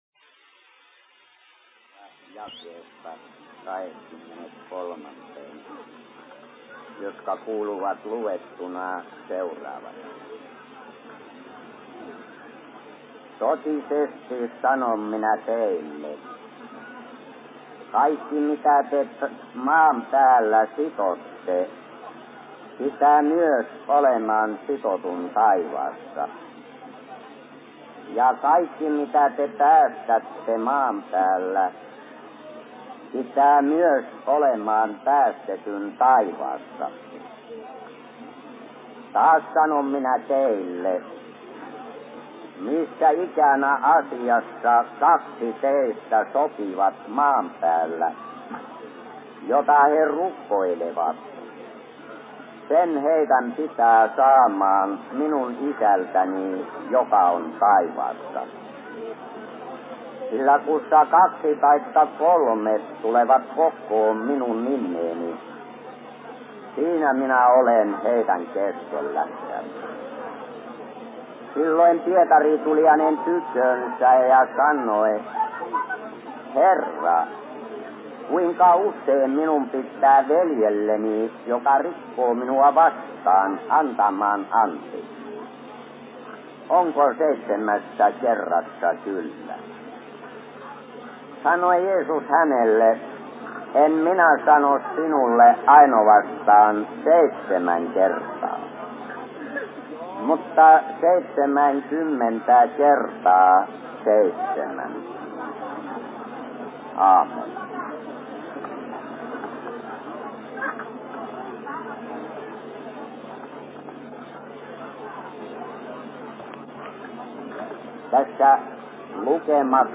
Suviseurat Kemissä/Seurapuhe 1955
Paikka: 1955 Suviseurat Kemissä